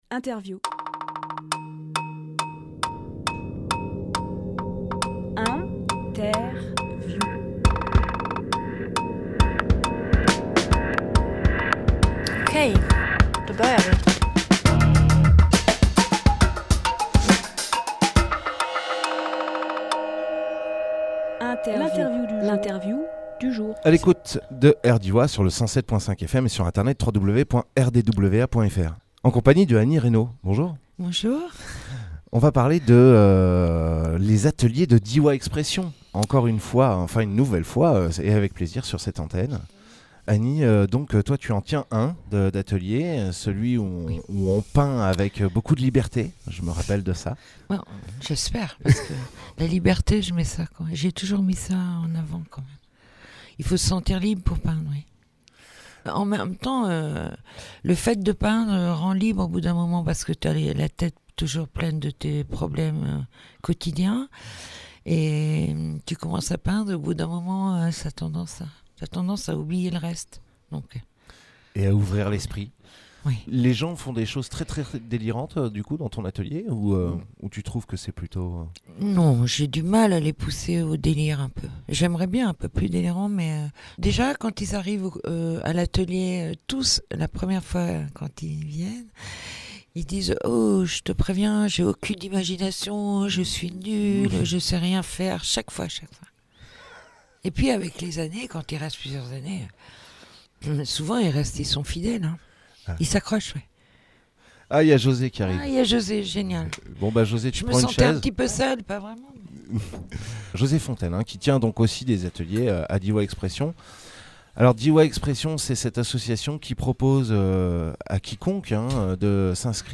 Emission - Interview L’expo de Diois Expressions Publié le 22 mai 2018 Partager sur…
Lieu : Studio RDWA